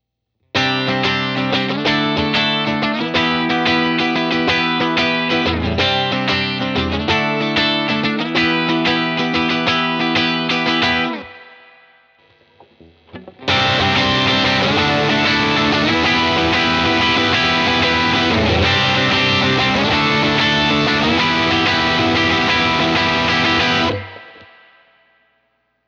さて肝心のOS2ですが、独立した二系統の歪み回路によるカラフルなサウンドが特徴的です。
使用環境ですが完全にライン撮りです。
使用アンプ:DREAM65【所謂デラックスリバーブのモデリング】
backingの方は最初はアンプ直の音。その後OS2を踏んでいます。
ギターはテレキャスターのリア【シングルコイル】です！
テレキャスター→OS2→エルキャビスタン→Dream65
BOSS OS2 TL backing